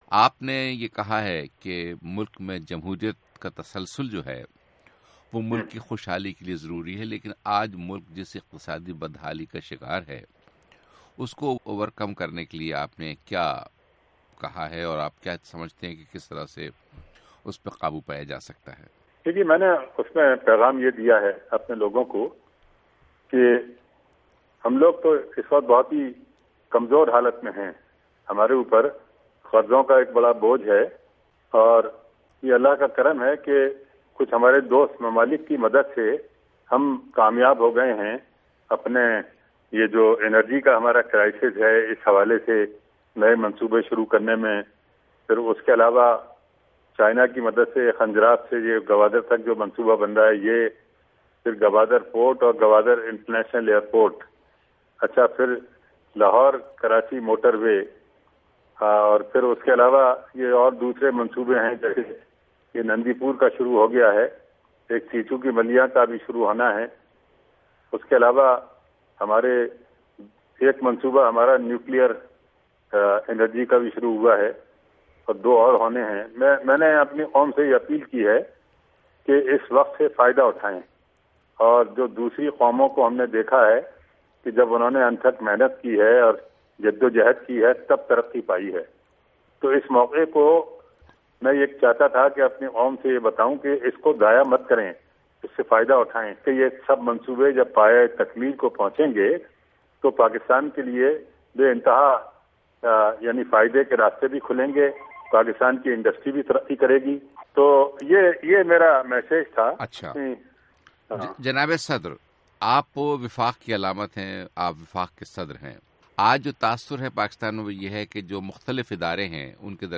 Interview with Pakistan President, Mamnoon Hussain